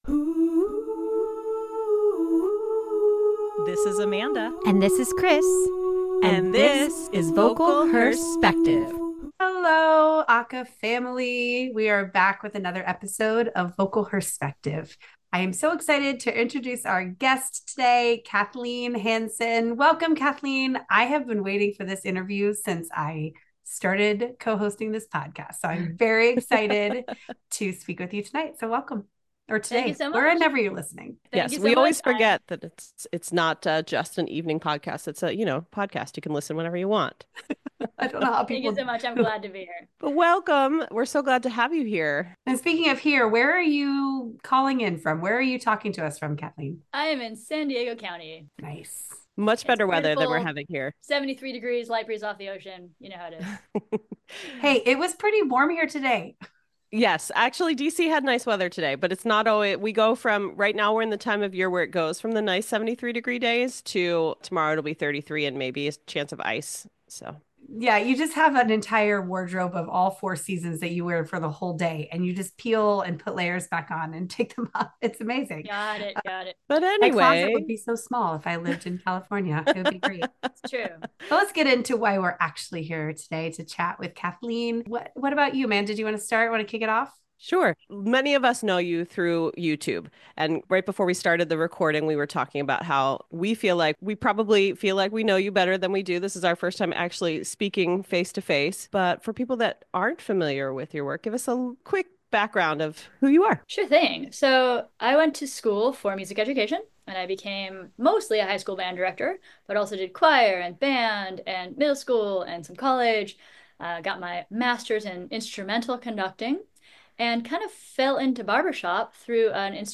Interviews from a feminine perspective